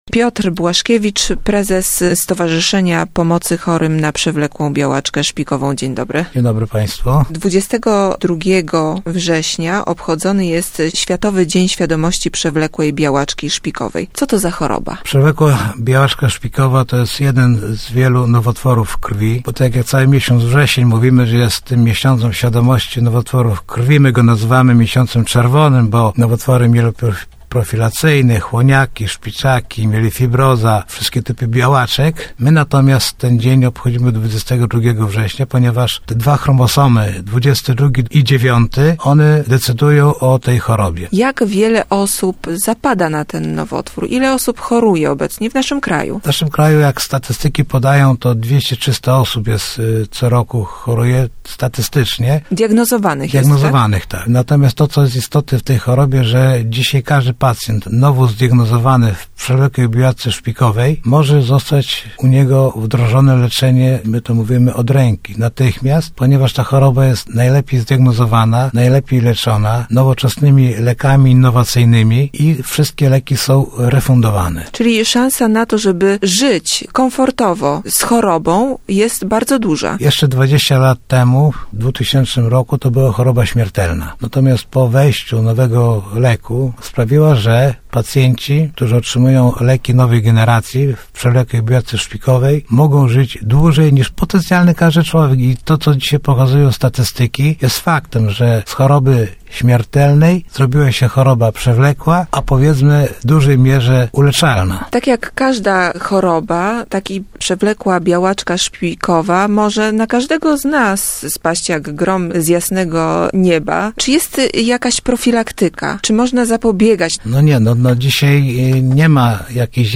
22 września obchodzony jest Międzynarodowy Dzień Świadomości Przewlekłej Białaczki Szpikowej. Przewlekła białaczka szpikowa to nowotwór, który powstaje i rozwija się w szpiku kostnym. Rozmowa